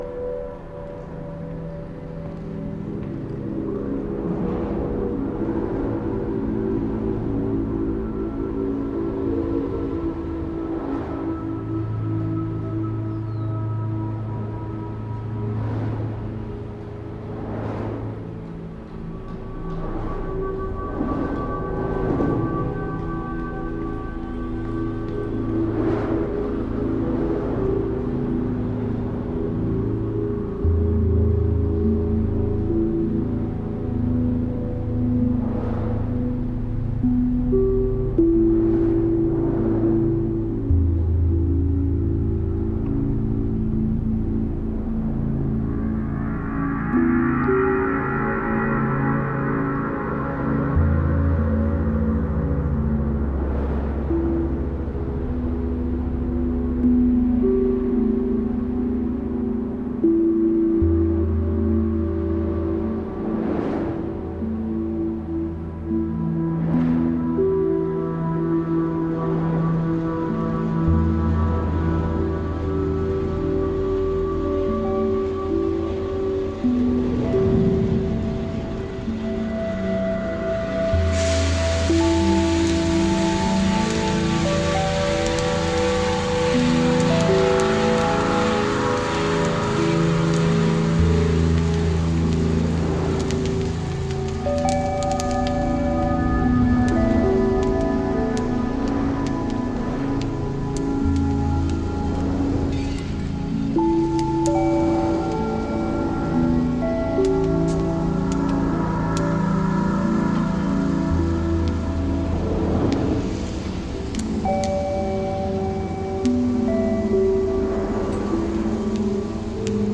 Groundbreaking ambient and dark-ambient.